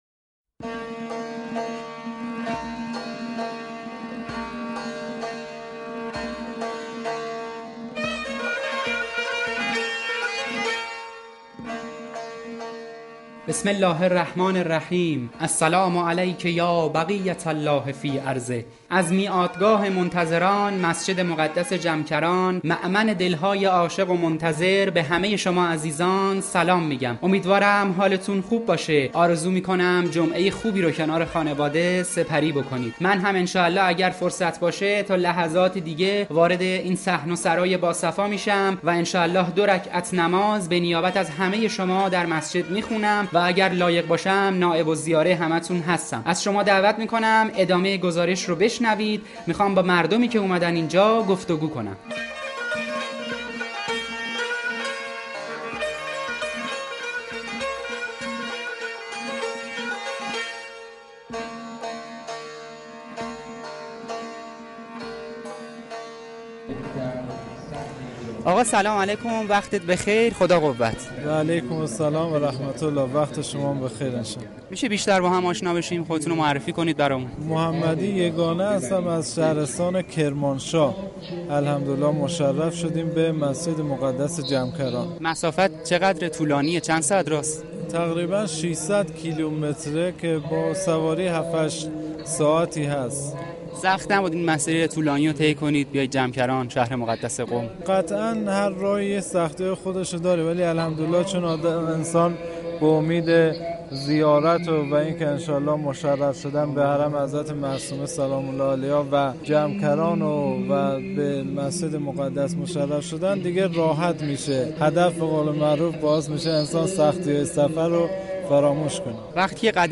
پادکست گزارشی از مسجد مقدس جمکران و گفت و گو با زائران و مجاوران درباره مهدویت و انتظار